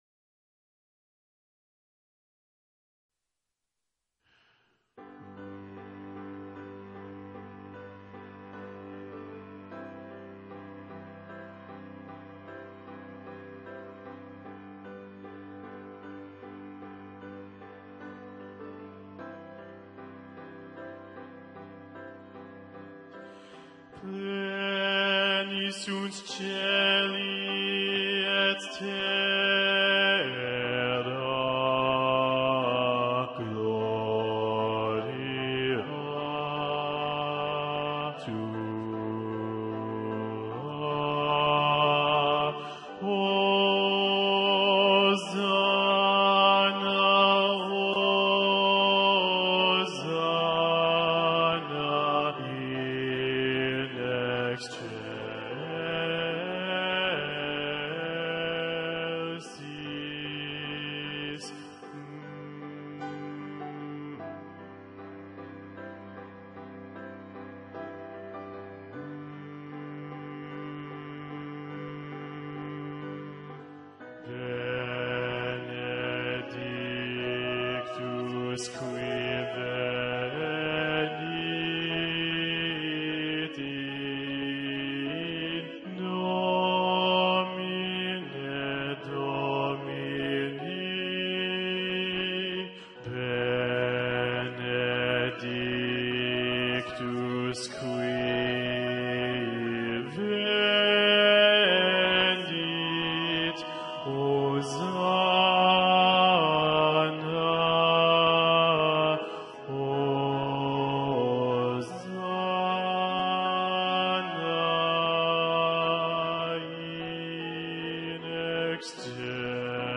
Répétition SATB par voix,  (mp3 sur le site, accès direct)
Basse 1
Ground Bass 1 Solo.mp3